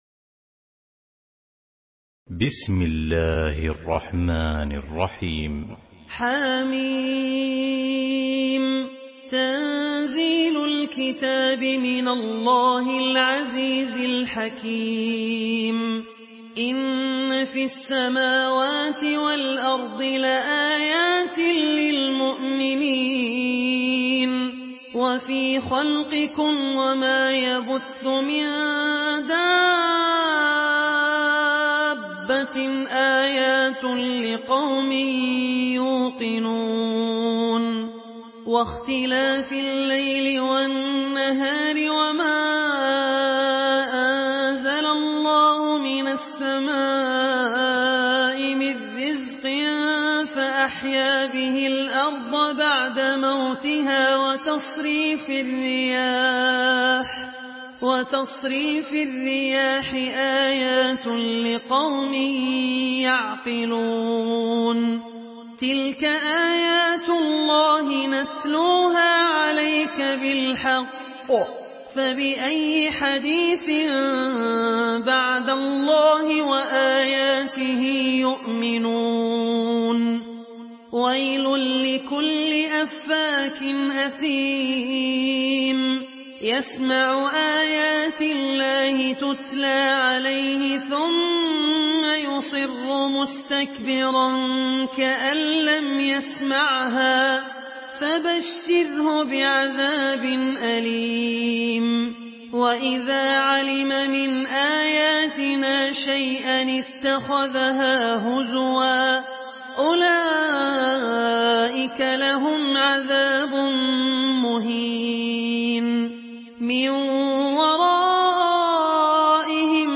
Surah Al-Jathiyah MP3 in the Voice of Abdul Rahman Al Ossi in Hafs Narration
Surah Al-Jathiyah MP3 by Abdul Rahman Al Ossi in Hafs An Asim narration.
Murattal Hafs An Asim